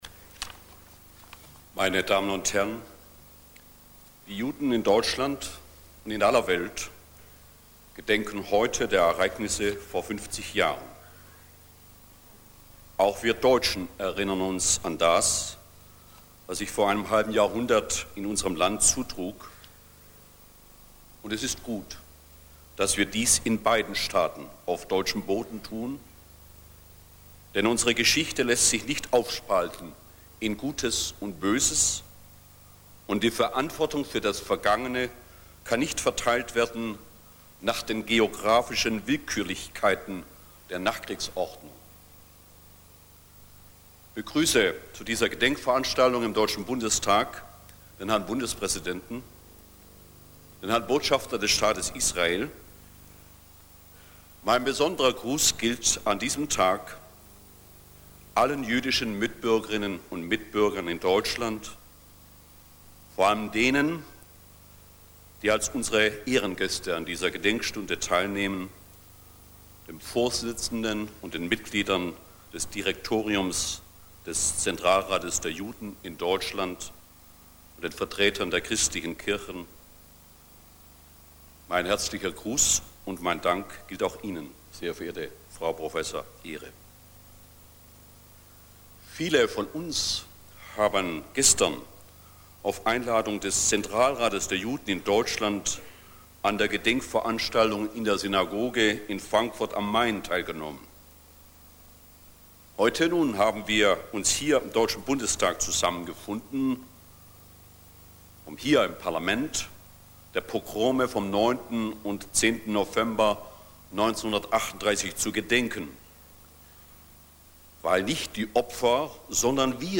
Rede am 10. November 1988 im Deutschen Bundestag
Er steht auch als Adobe PDF , als Office Document und als Tondatei (Warnung: Der Vortragsstil des Redners ist wirklich eine Zumutung) zur Verfügung.